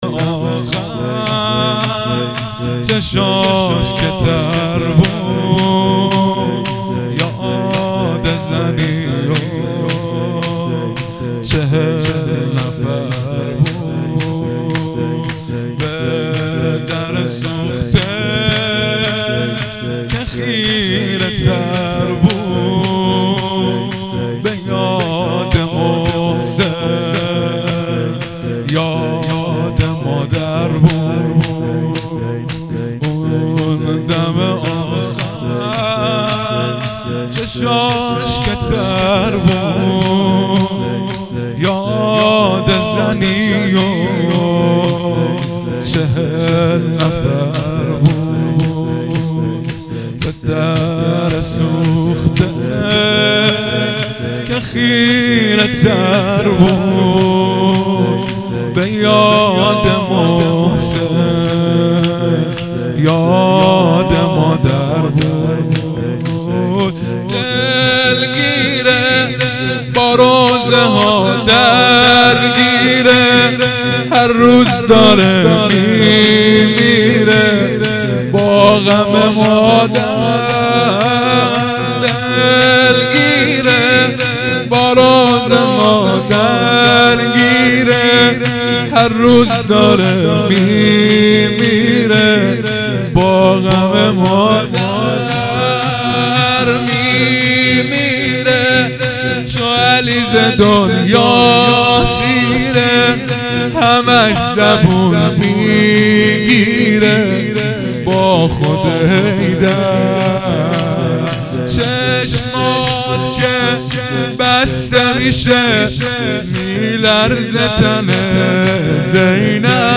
فایل های صوتی مراسم هفتگی مرداد 94
haftegi-15-mordad-94-zamineh.mp3